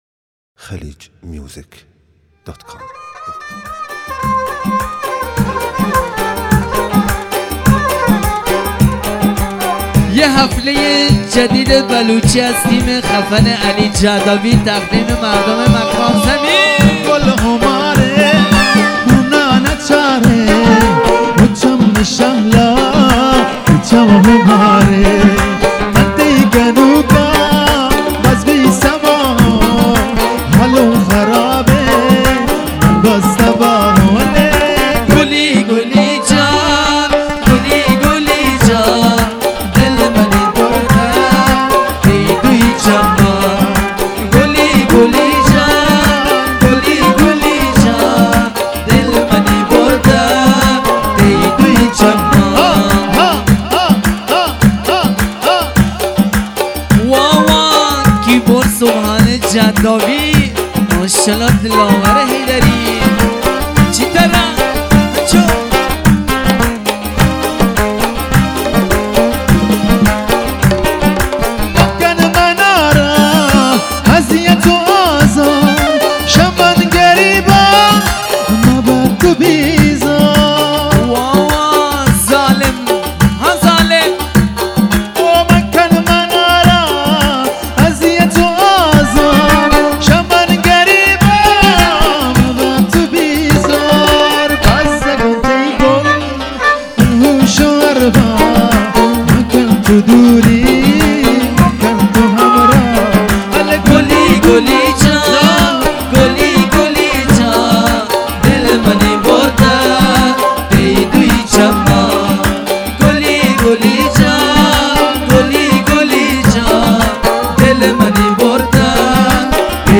دسته: حفله ها